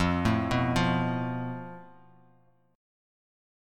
FMb5 chord